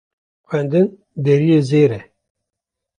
Pronunciado como (IPA)
/zeːɾ/